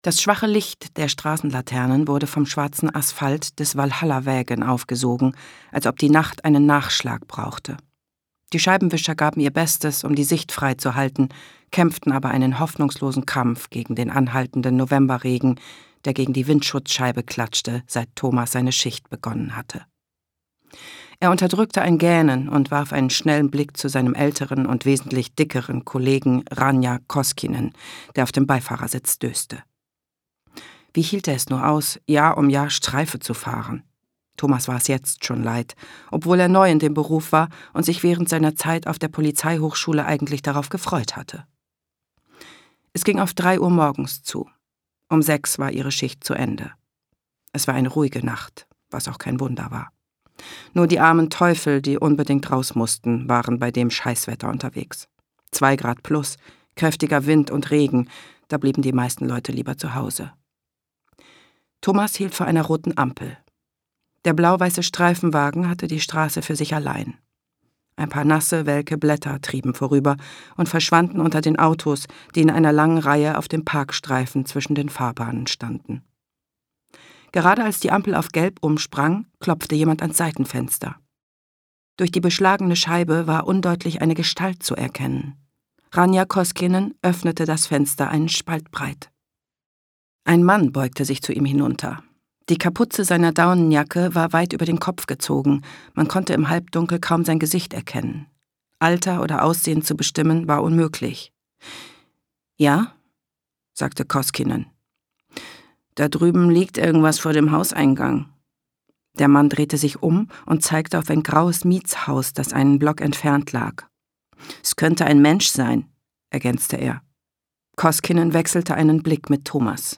Mitarbeit Sprecher: Katja Danowski